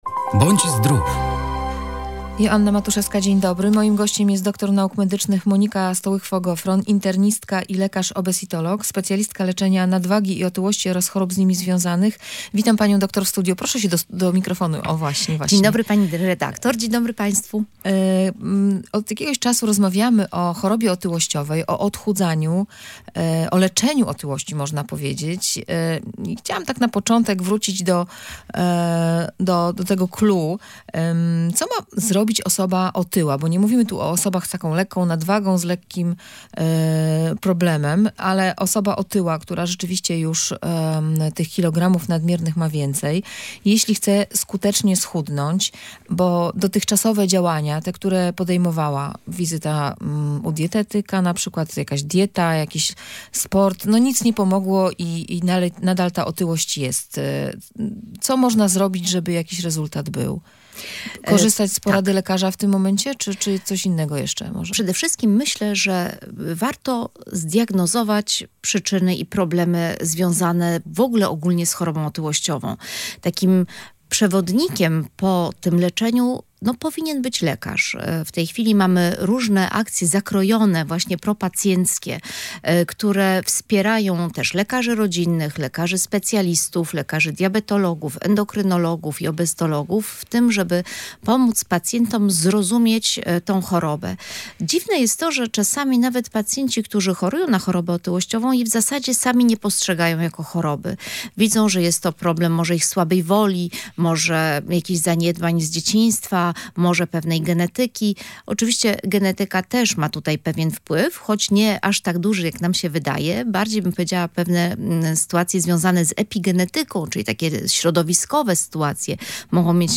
Lekarz obesitolog o lekach stosowanych w leczeniu choroby otyłościowej